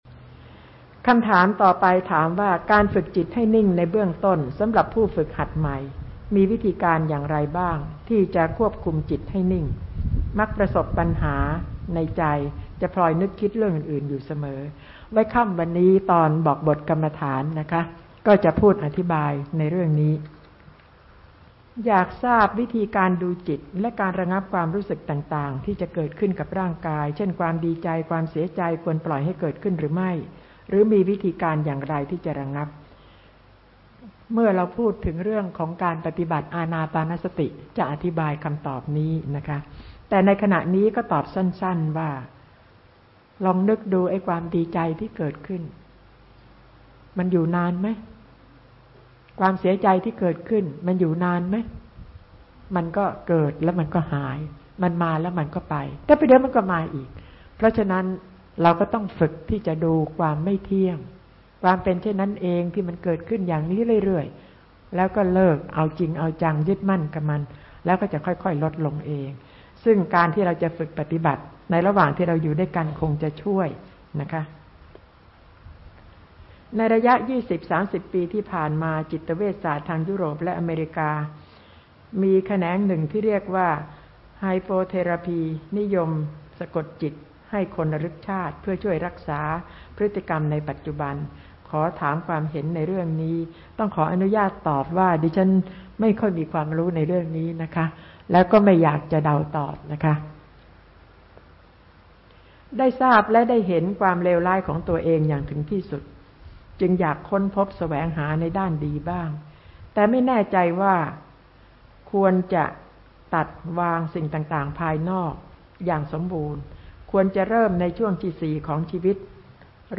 ตอบปัญหา